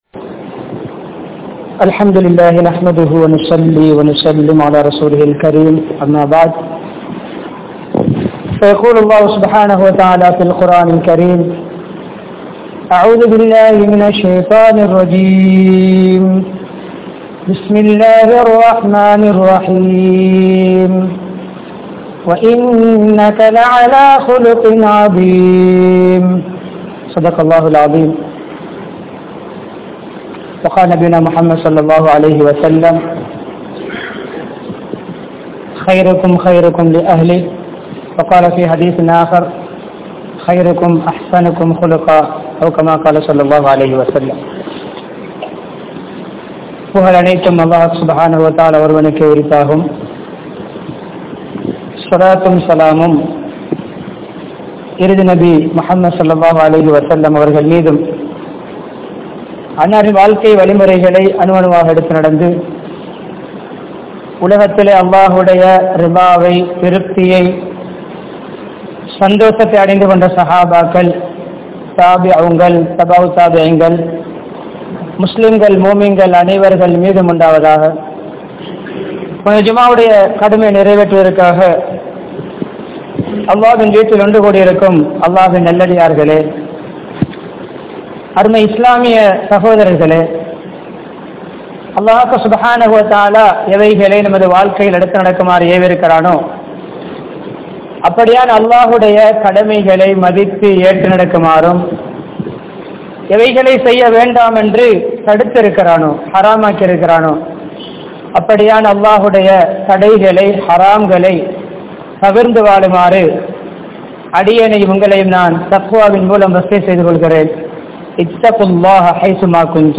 Nabi(SAW)Avarhal Pirarukku Seitha Uthavihal (நபி(ஸல்)அவர்கள் பிறருக்கு செய்த உதவிகள்) | Audio Bayans | All Ceylon Muslim Youth Community | Addalaichenai